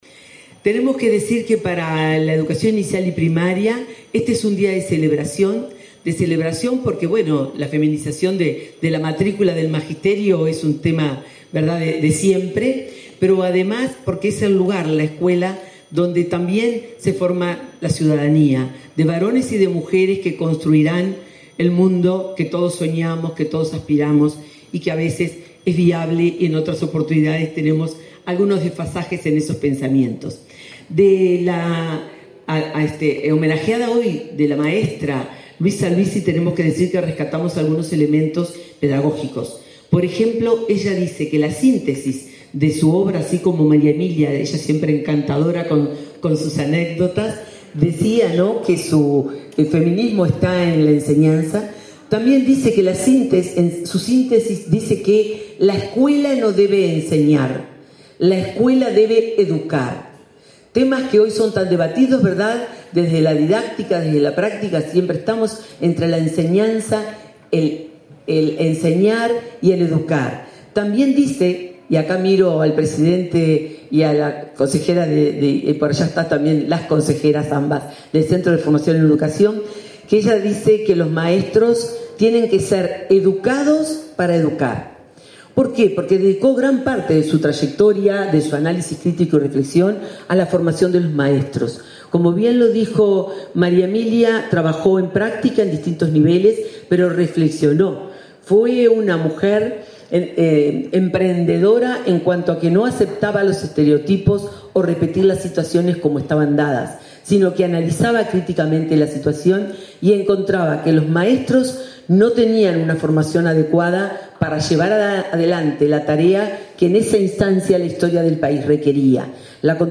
Palabras de la directora de Primaria, Graciela Fabeyro
En el marco del Día Internacional de la Mujer, la Administración Nacional de Educación Pública (ANEP) realizó un homenaje a la poetisa Luisa Luisi.